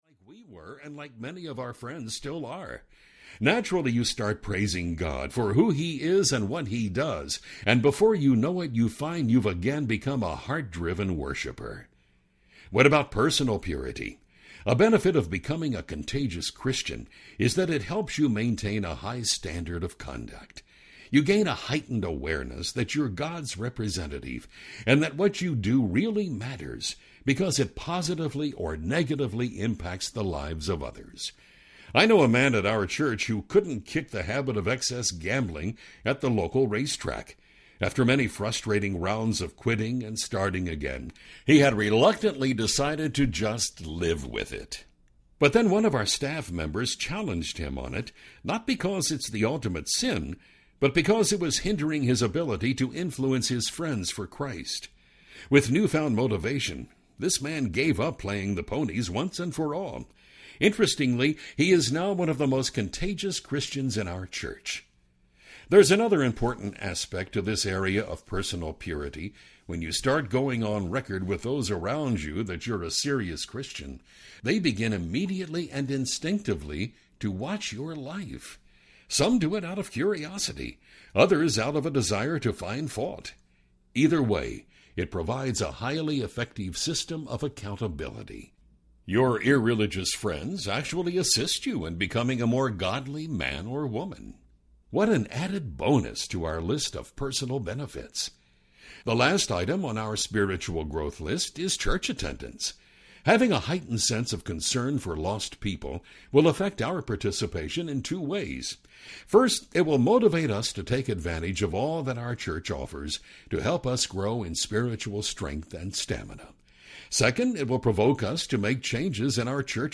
Becoming a Contagious Christian Audiobook
Narrator
7.4 Hrs. – Unabridged